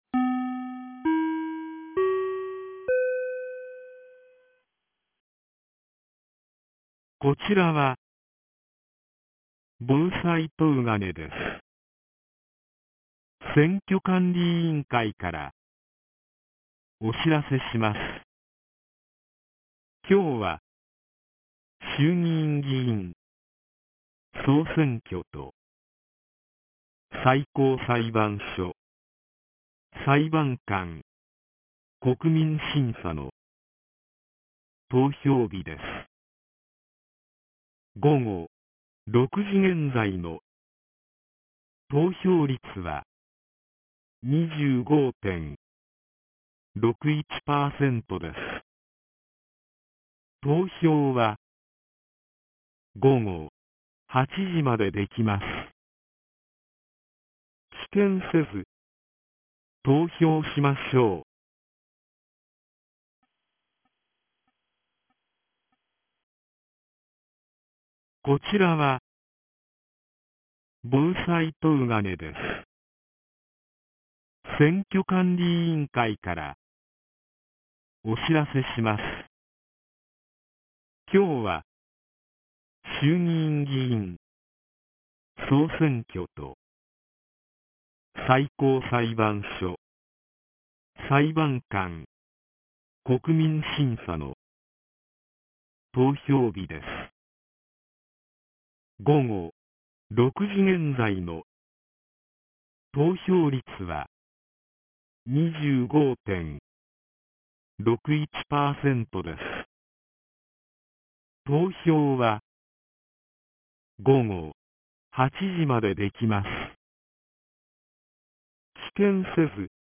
2024年10月27日 18時12分に、東金市より防災行政無線の放送を行いました。